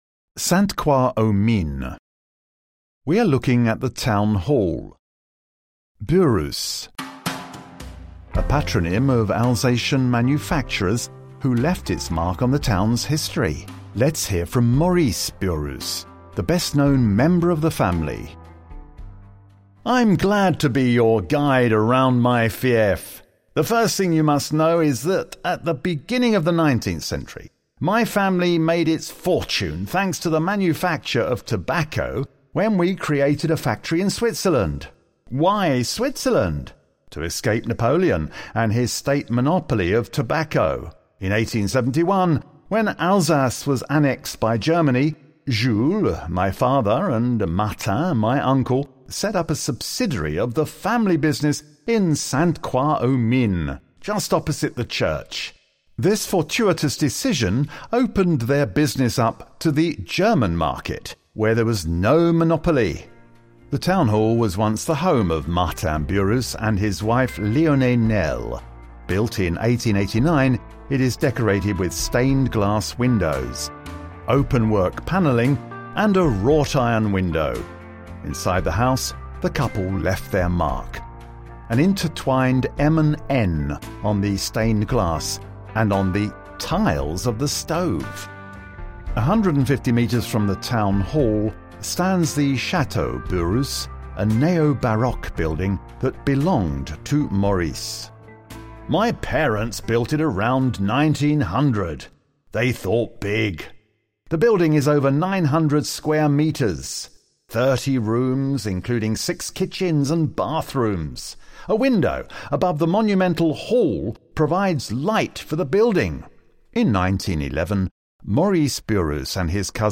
Audioguides pour Sainte-Croix-aux-Mines, Lièpvre, Rombach-le-Franc
• Audioguide 9 : le patrimoine des Burrus à Sainte-Croix-aux-Mines : fr